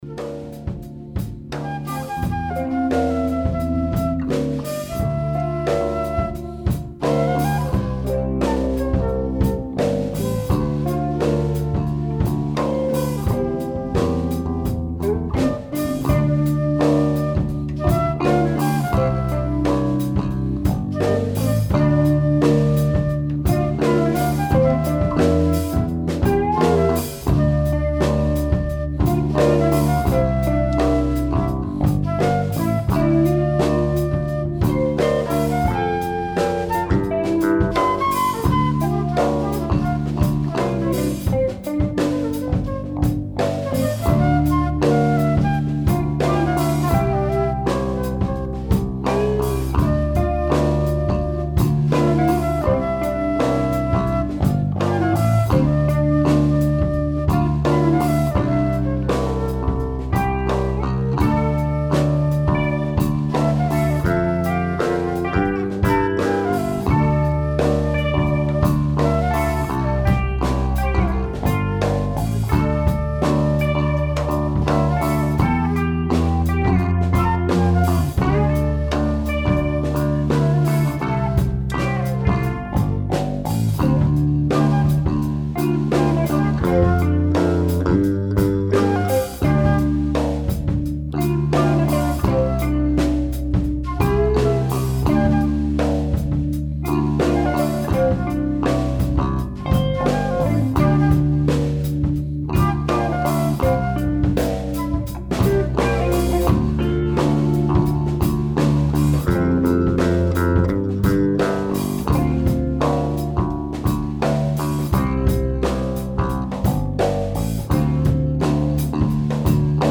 Funk, Soul, Jazz & Ska; Blues.
Drums, Percussions
Guitar, Bass, Vocals
Keys, Bass, Vocals
Sax, Flute